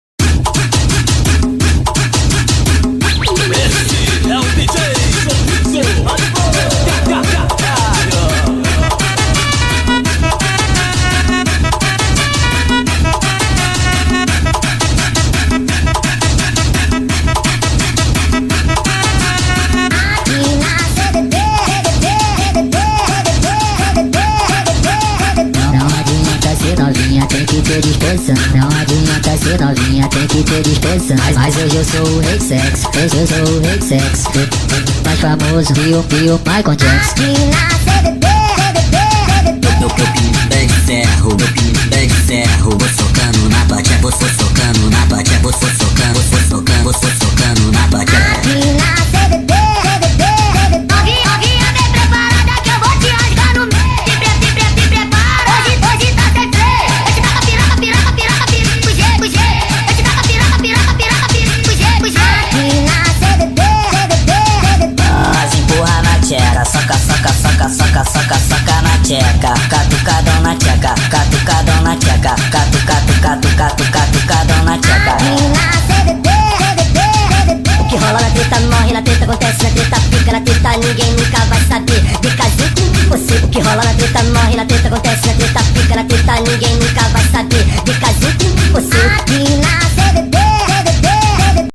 2025-02-16 00:22:55 Gênero: Phonk Views